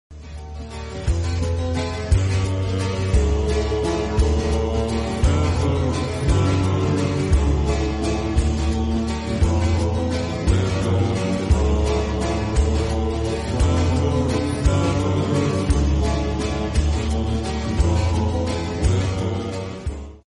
medieval vibes